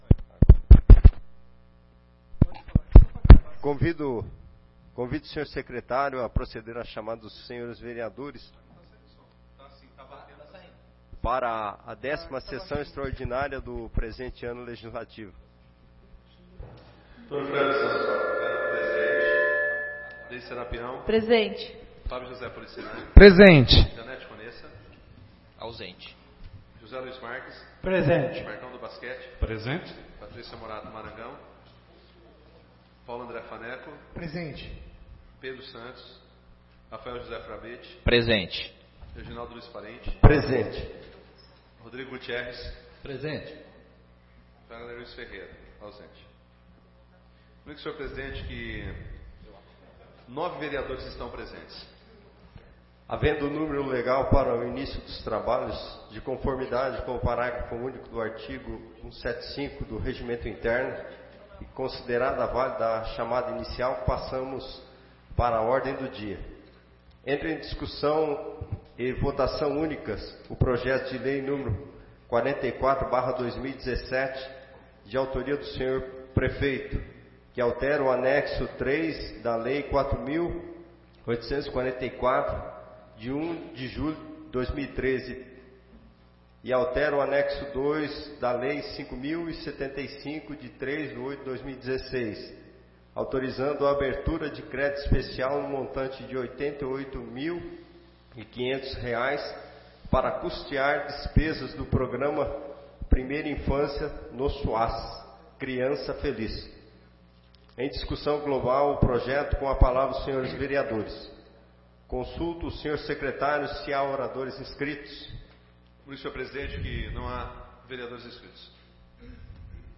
10ª Sessão Extraordinária de 2017